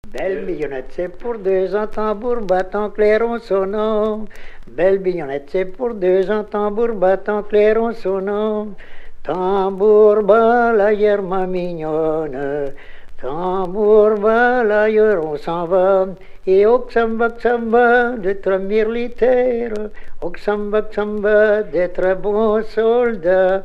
Belle mignonnette Cette Pièce musicale inédite a pour titre "Belle mignonnette".
voix seule
gestuel : à marcher